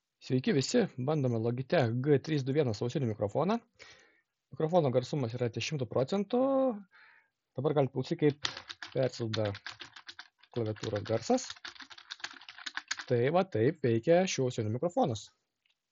Mikrofonas
„Logitech G321 Lightspeed“ turi atlenkiamą nelankstų mikrofoną, o tai apriboja galimybes pasirguliuoti atstumą iki burnos. Galite paklausyti kokį garsą perduoda šis mikrofonas, tai nėra aukščiausio lygio garsas, bet nėra ir tragedijos.